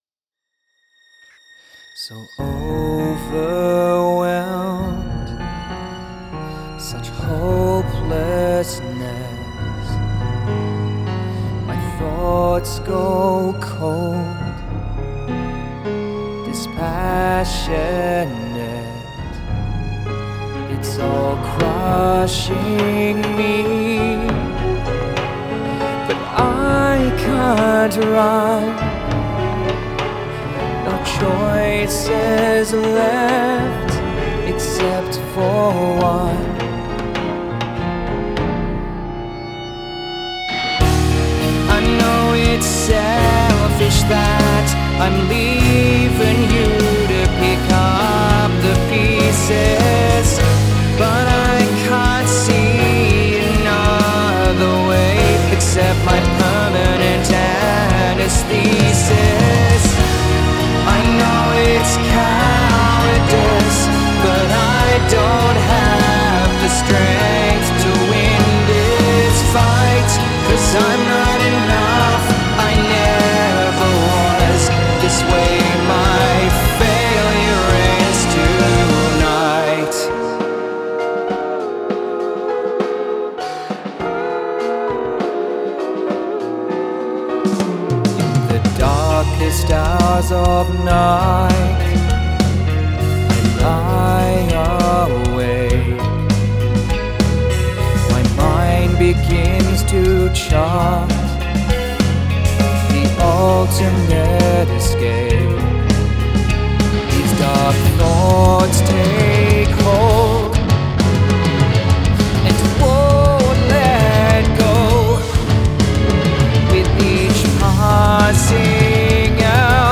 metal song
the darkest piece I've ever written